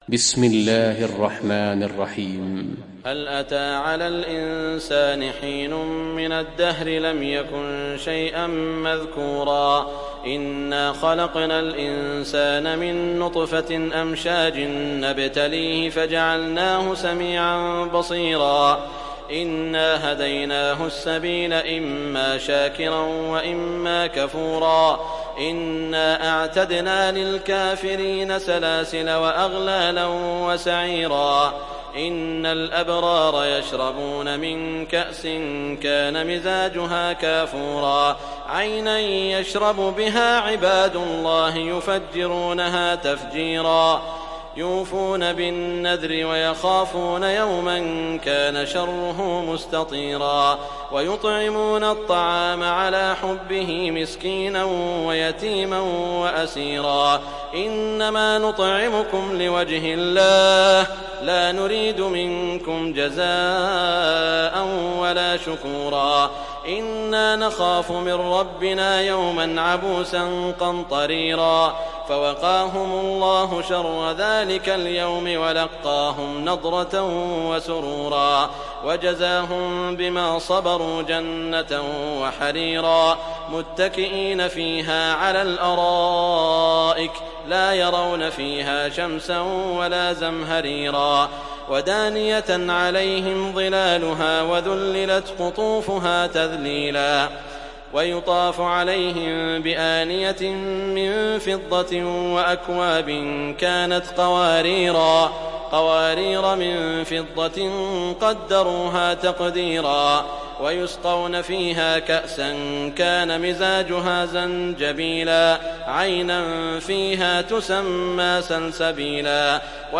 Surat Al Insan mp3 Download Saud Al Shuraim (Riwayat Hafs)